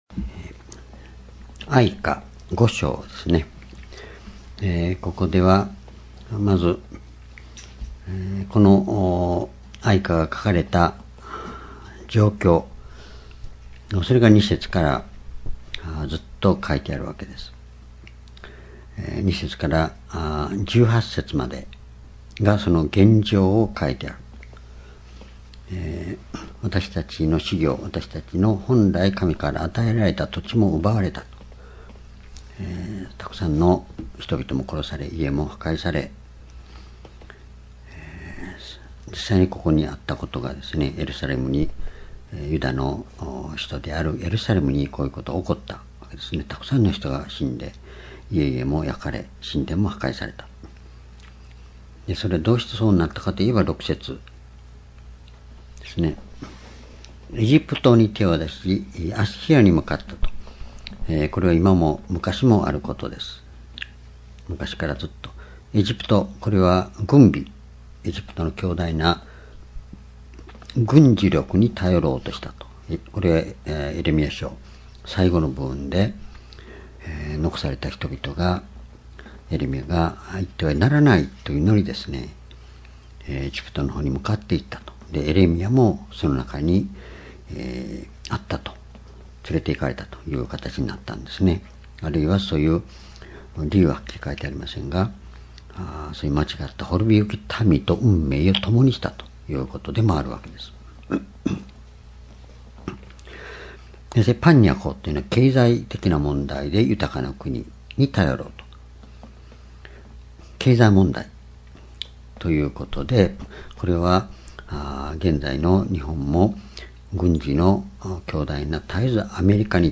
｢永遠の神に立ち返る｣哀歌5章-2018年5月15日 夕拝